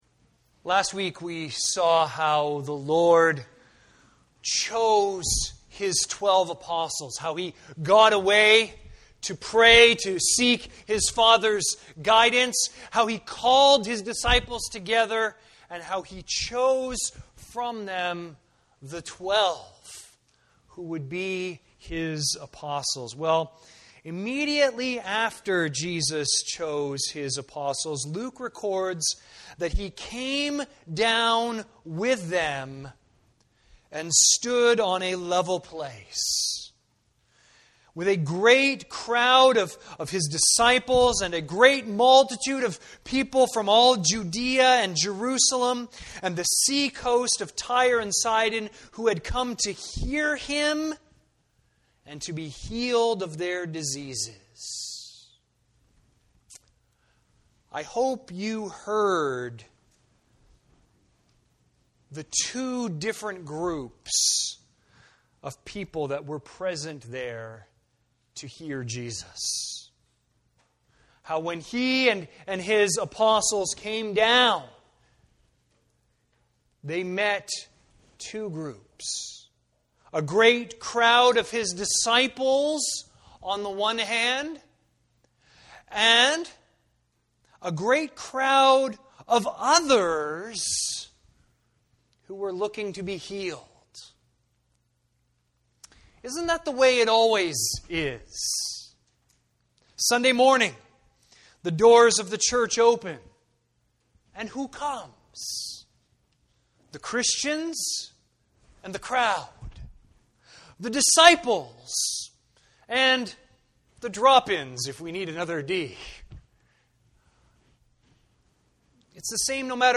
View the Sunday service.